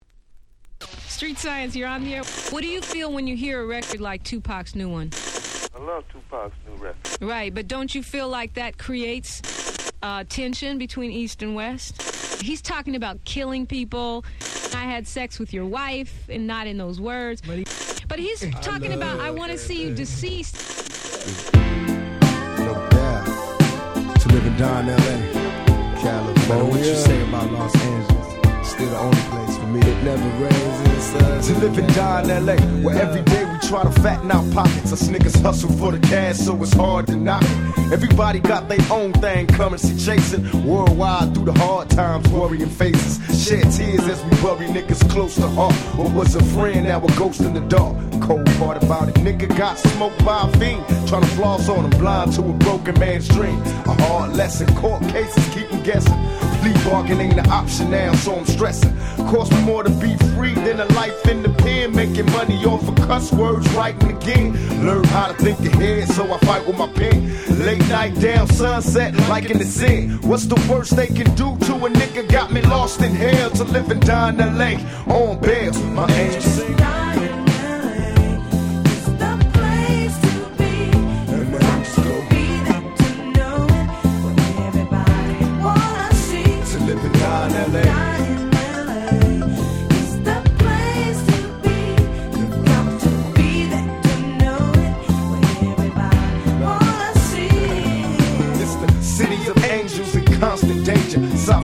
90's West Coast Hip Hop Classics !!
両面共に日本人受け抜群のメロディアスな楽曲でオススメ！！
G-Rap Gangsta Rap